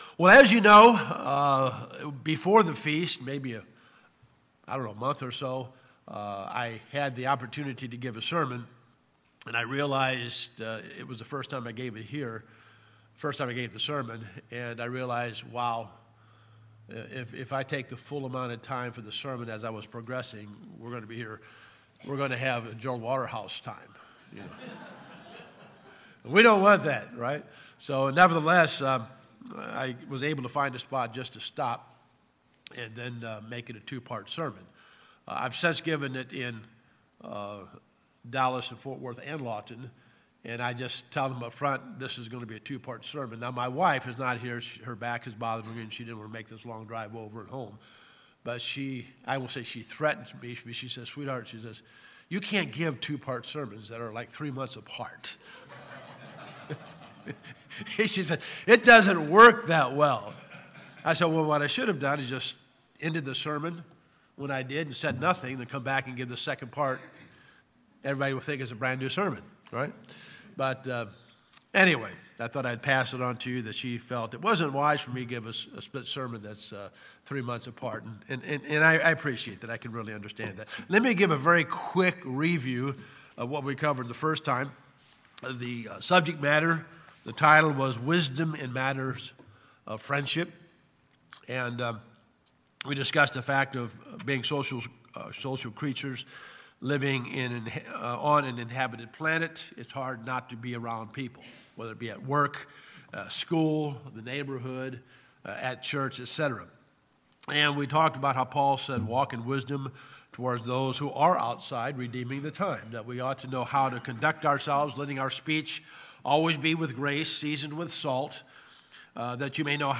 Decisions in matters of friendship can be life altering in a positive or negative way. God's word provides wisdom in developing good relationships, selecting friends and avoiding pitfalls. In part two of this sermon, learn wisdom in maintaining good friendships and how to solve problems with friends.